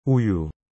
O que significa e como pronunciar “uyu”?
A pronúncia correta é “u-yu”, com um som suave e fluido.